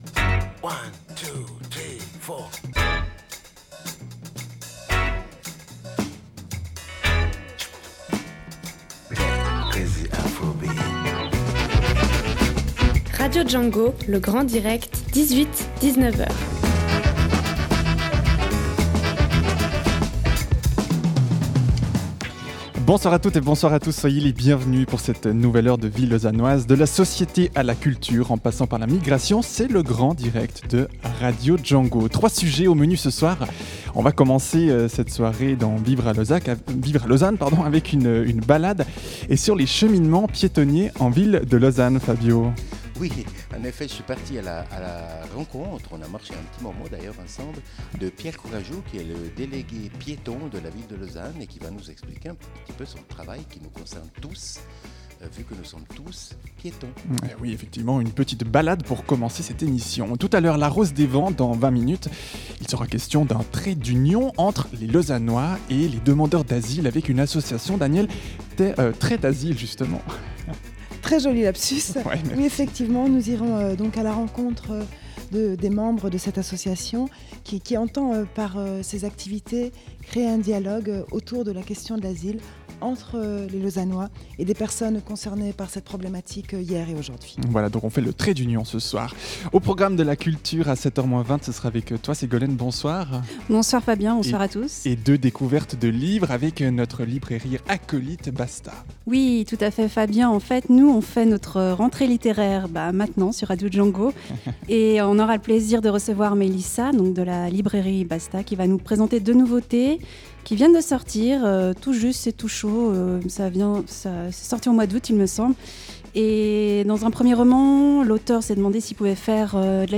Ce soir dans le Grand Direct, l’équipe est au micro en direct de Pôle sud même pendant les vacances, pour vous faire vivre une nouvelle émission!